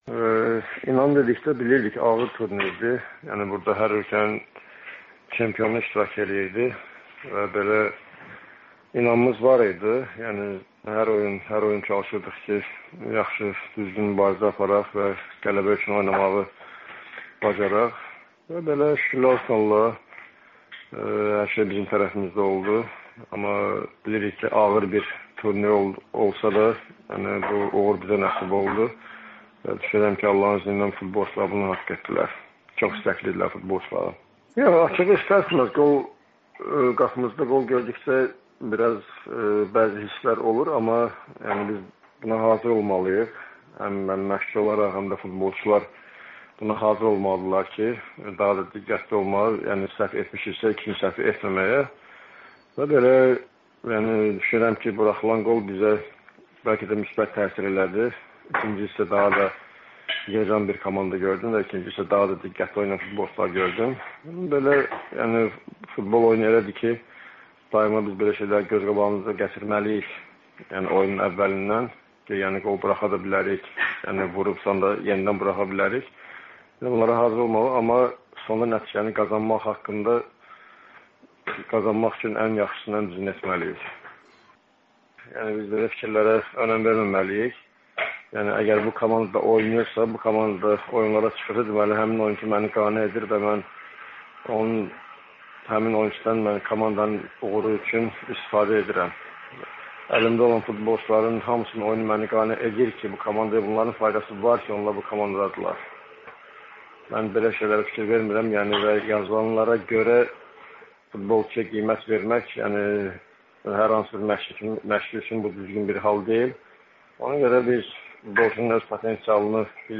«Düşünürəm ki, buraxılan qol bəlkə də bizə müsbət təsir etdi. İkinci hissədə daha toparlanmış komanda və daha diqqətli oynayan futbolçular gördüm», - «Qarabağ»ın baş məşqçisi, Azərbaycan futbol tarixində ilk dəfə komandası UEFA Çempionlar Liqasının qrupuna düşən Qurban Qurbanov AzadlıqRadiosuna müsahibəsində belə deyib.